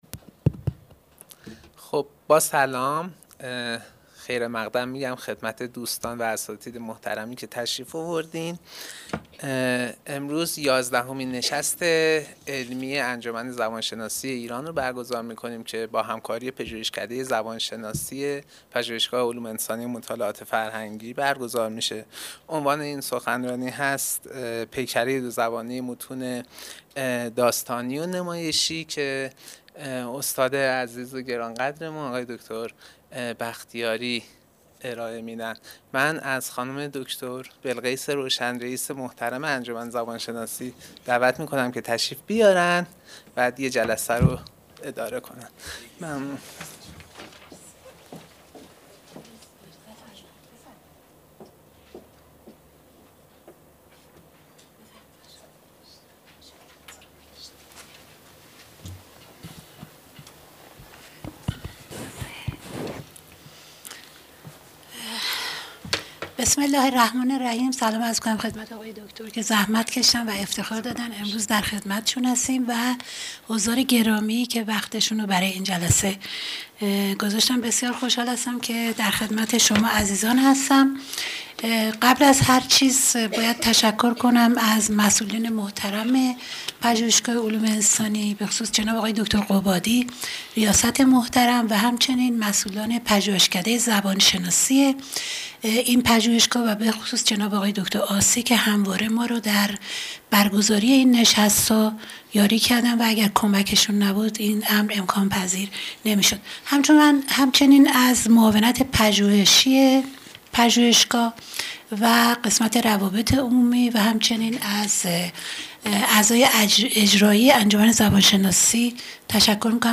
سالن حکمت